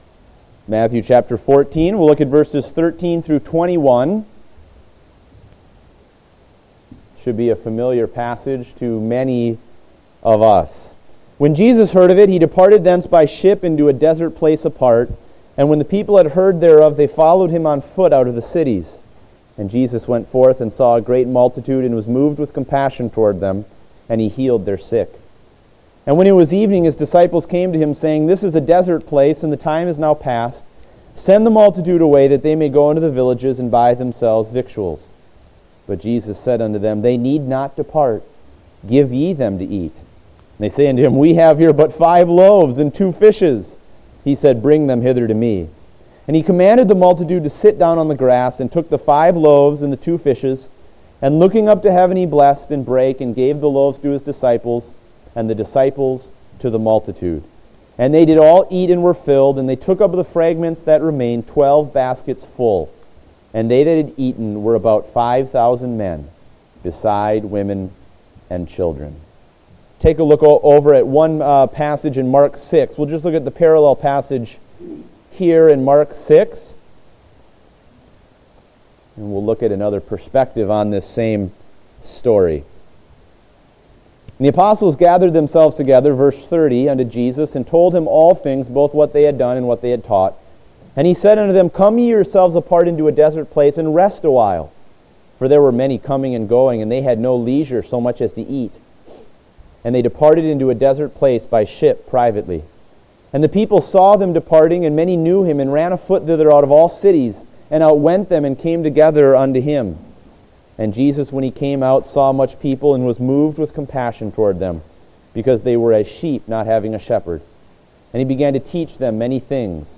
Date: December 6, 2015 (Adult Sunday School)